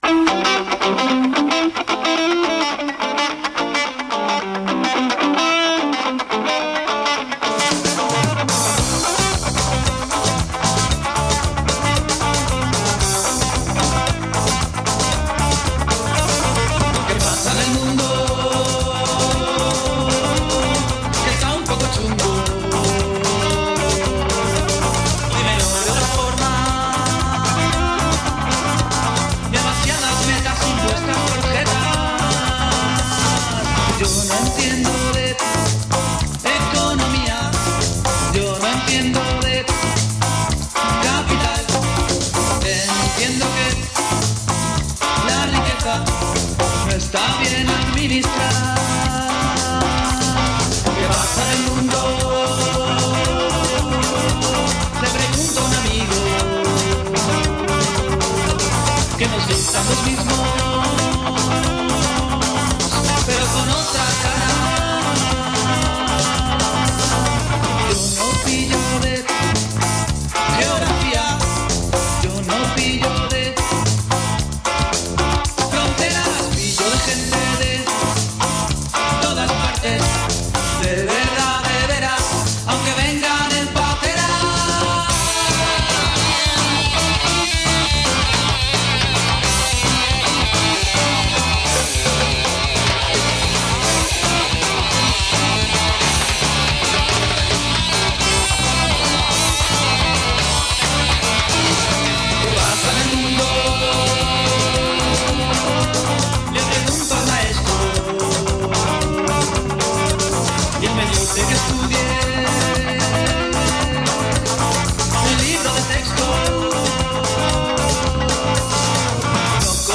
Áudio de un ex-policia comentando las declaraciones de mossos d’esquadra en un programa de televisión.